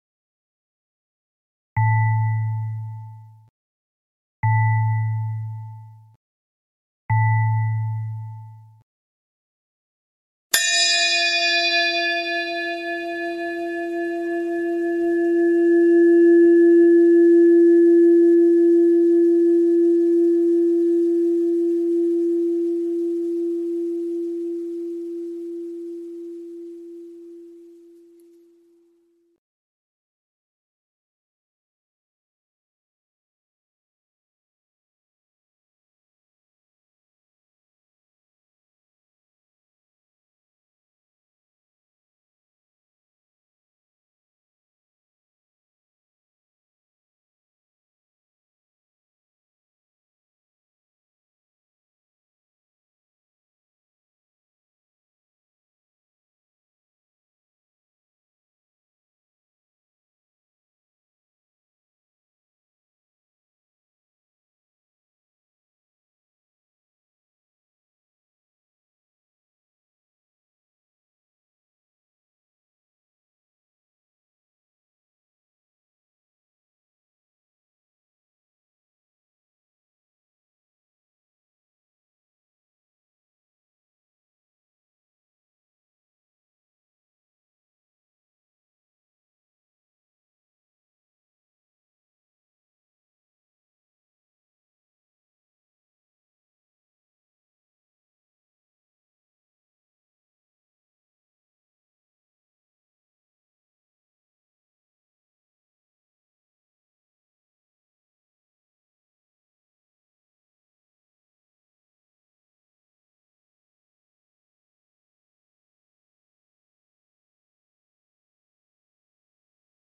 Interval Chimes for the Shambhavi Meditation.